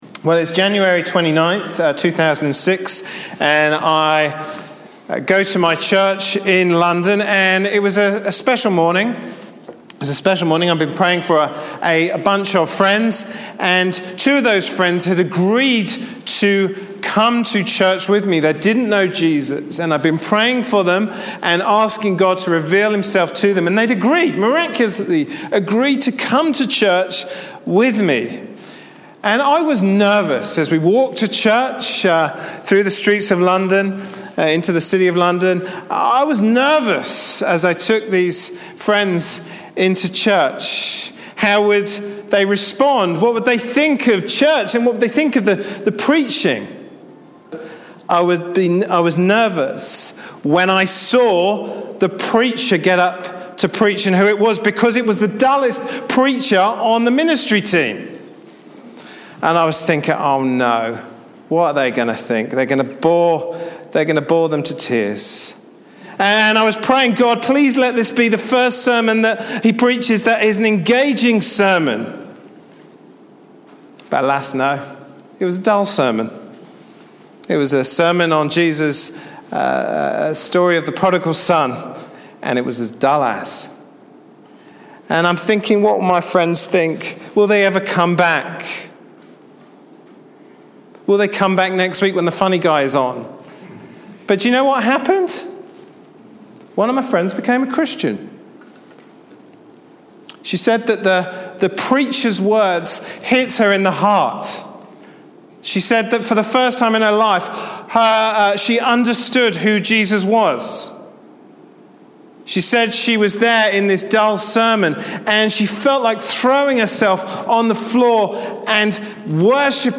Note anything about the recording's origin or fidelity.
Passage: Acts 2:22-47 Service Type: Sunday morning service